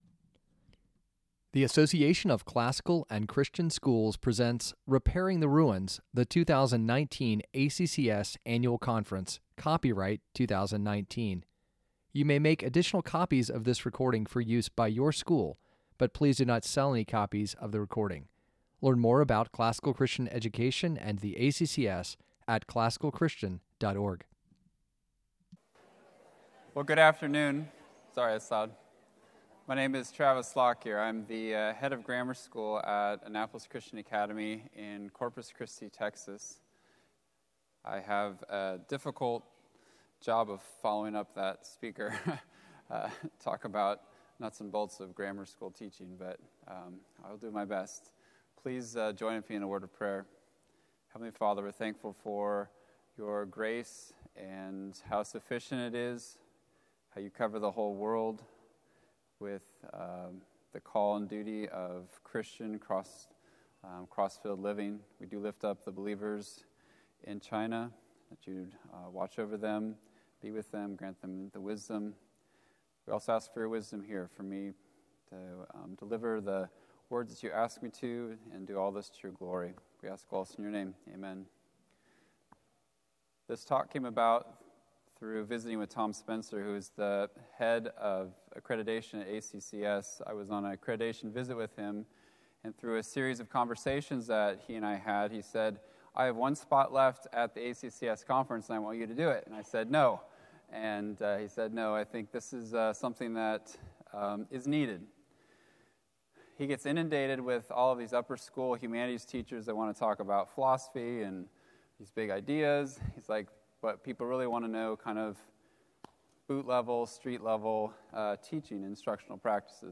2019 Workshop Talk | 01:09:42 | K-6, Teacher & Classroom